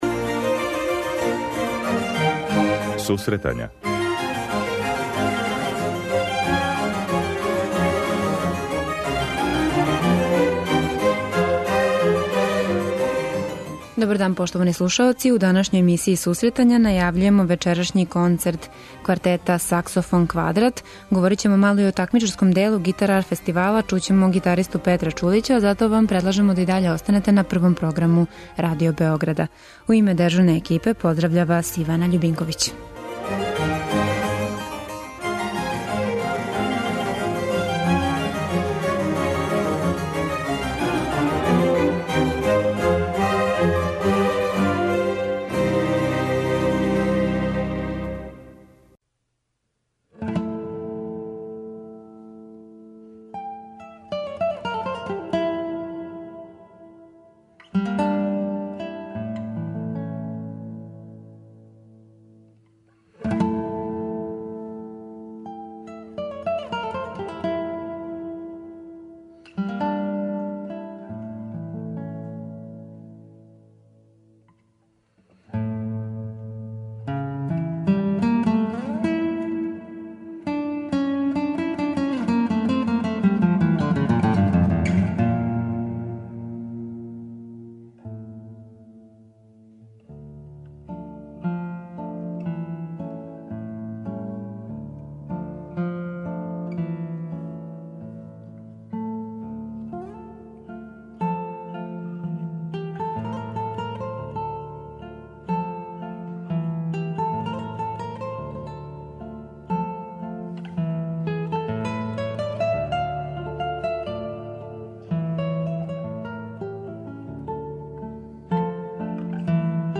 У данашњој емисији Сусретања најављујемо концерт квартета Saxofonquadrat који долази из Немачке, чућемо разговор са музичарима овог ансамбла